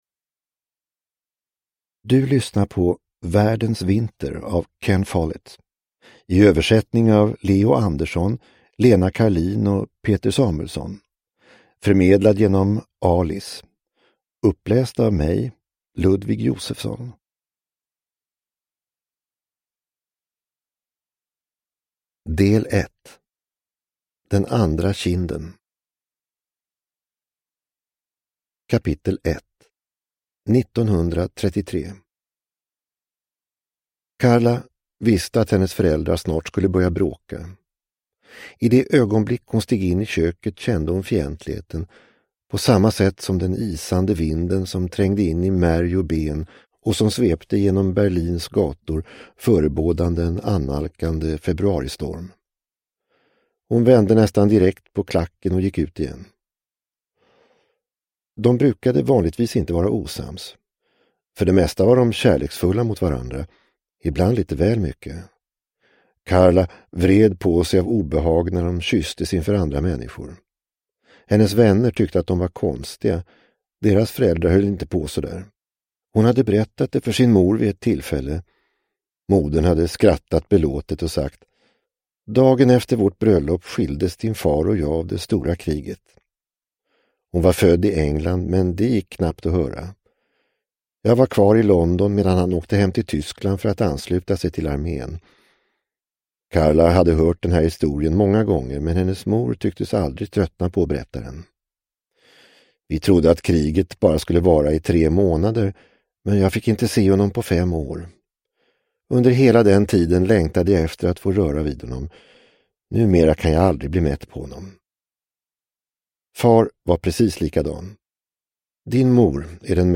Världens vinter – Ljudbok – Laddas ner